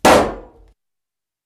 Balloon-Burst-05
balloon burst pop sound effect free sound royalty free Sound Effects